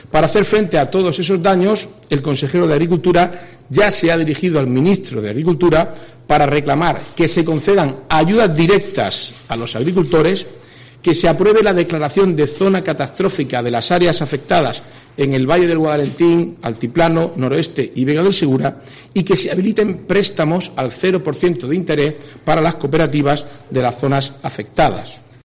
Marcos Ortuño, consejero en funciones de Presidencia, Turismo, Cultura, Juventud, Deportes y Portavocía
Así lo ha hecho saber el portavoz del Ejecutivo murciano, Marcos Ortuño, en la rueda de prensa posterior al Consejo de Gobierno, en la que ha remarcado la "apuesta" de la Comunidad por un sector agrícola "competitivo, eficiente y generador de puestos de trabajo".